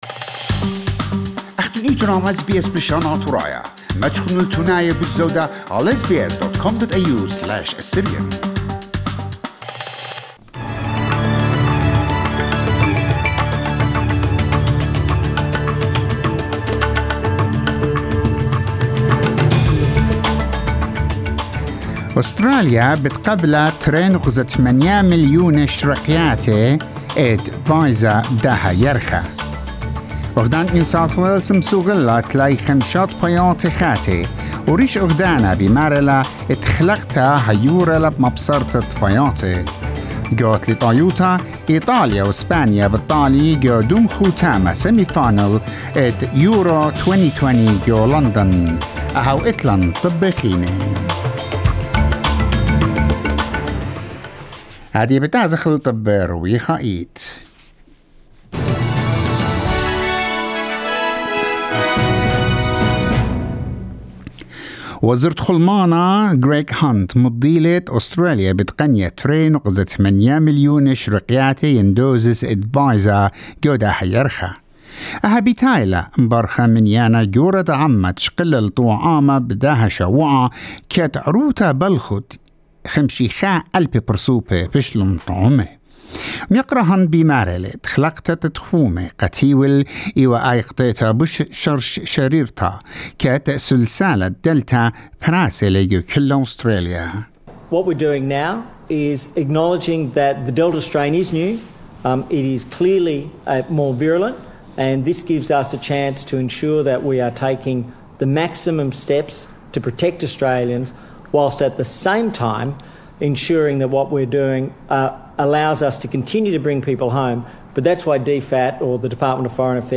SBS News in Assyrian Saturday 3 July 2021